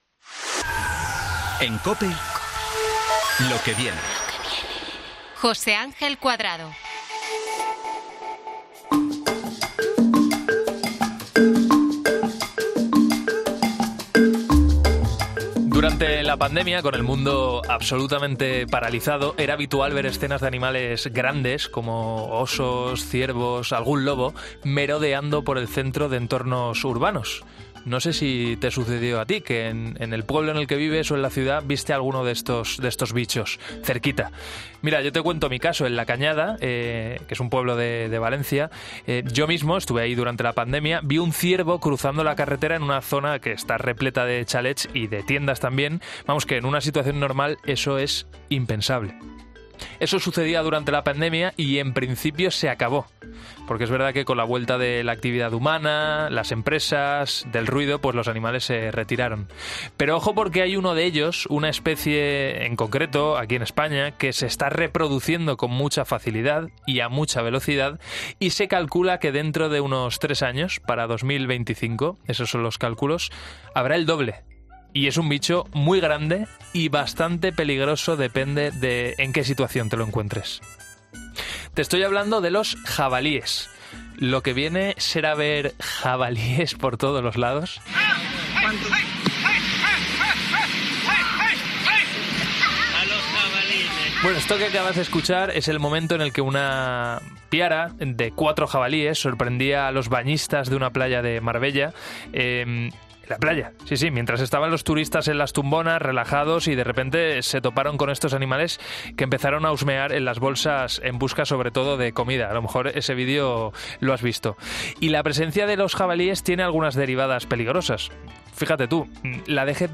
explica en 'Lo que viene' de COPE qué está pasando con la...